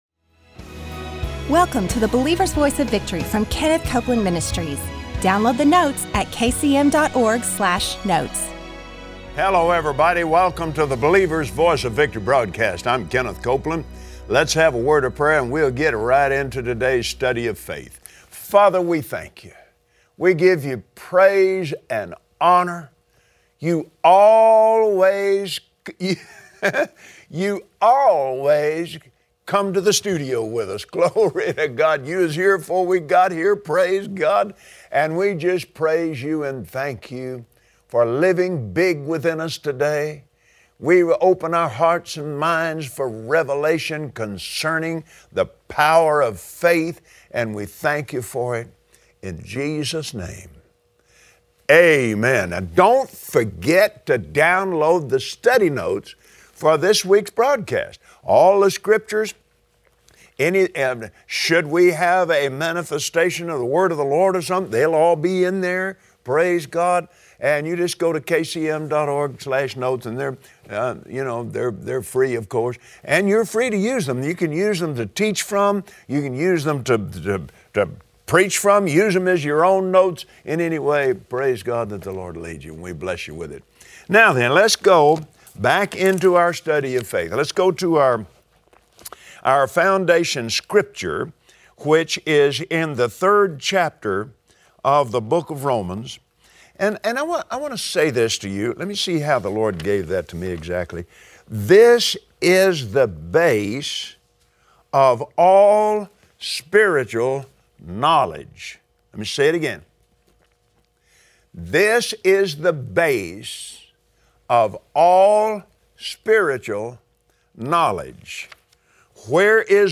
You have the power to choose the words that will activate those laws. Join Kenneth Copeland today, on the Believer’s Voice of Victory, as he reveals from scripture that faith filled words dominate.